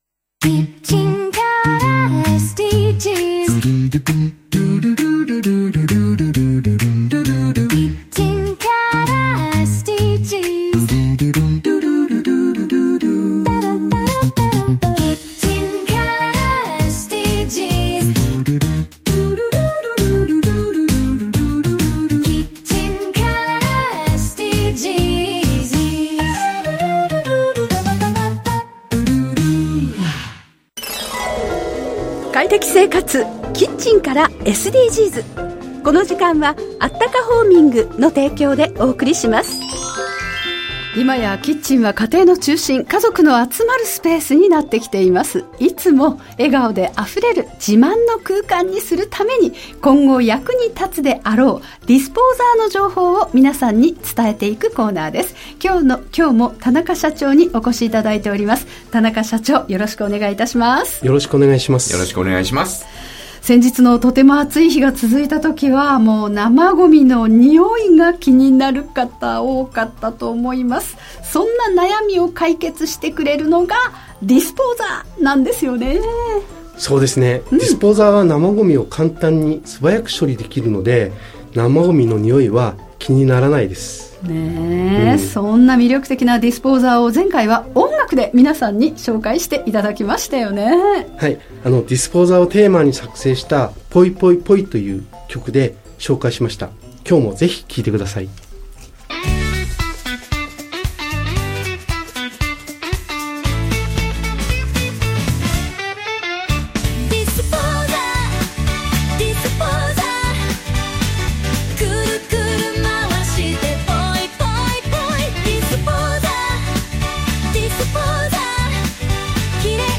【ラジオ】快適生活 キッチンからSDGs 放送中 （ じゃらら（JAGA）10時30分～45分 ）